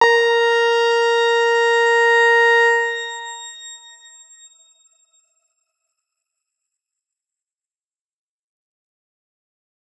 X_Grain-A#4-mf.wav